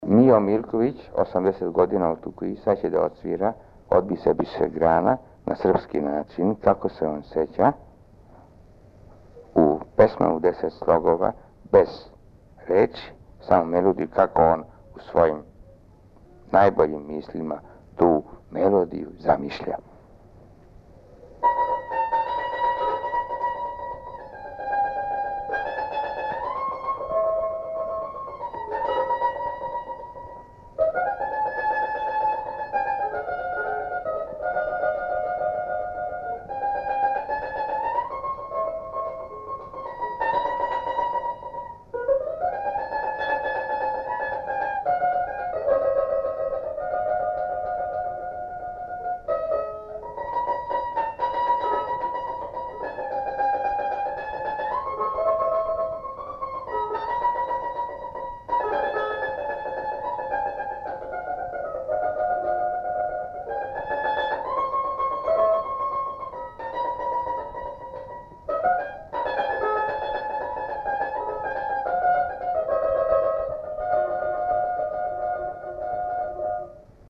Tamburán játszotta
Megjegyzés: Lakodalmas dallamok „szerb módra”, ahogyan a felvételen is bemondják. Rubato dallam, ami összeér a logovac dallamával.